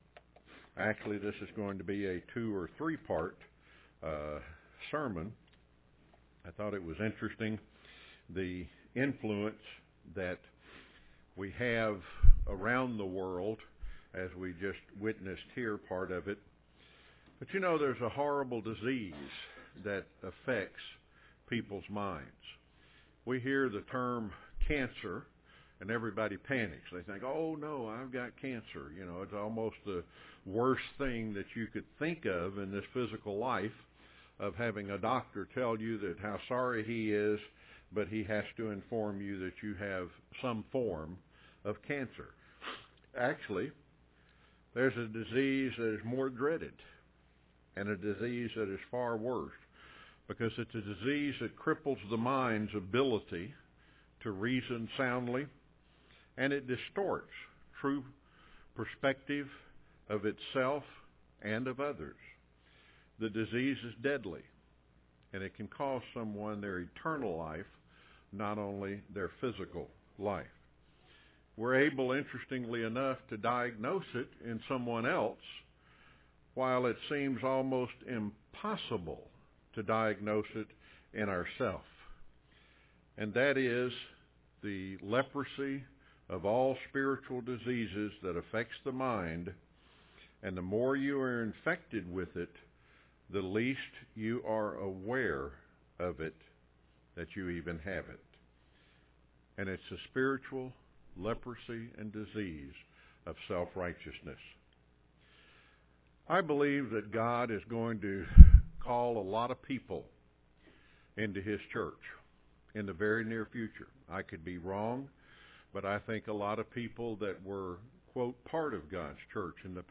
Given in Rome, GA
UCG Sermon Studying the bible?